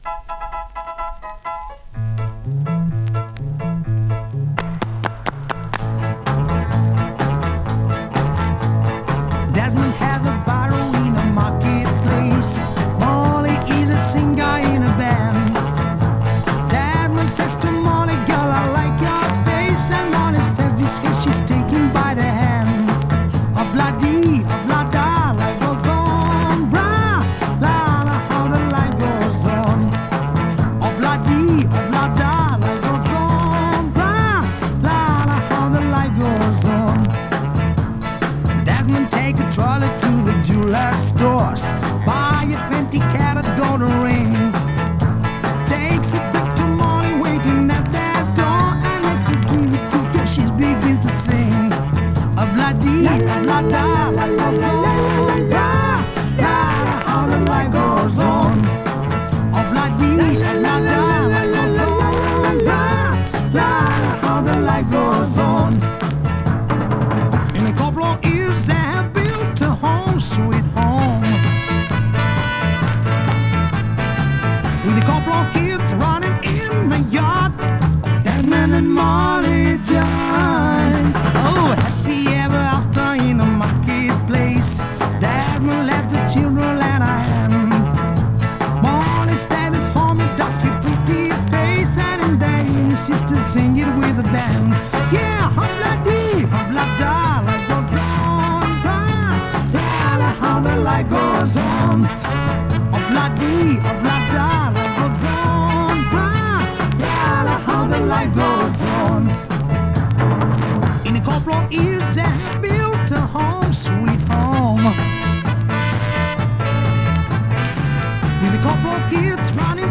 VESYOLYE REBIATA VOCAL-INSTRUMENTAL ENSEMBLE
(7" flexi EP)
Mono or Stereo: Mono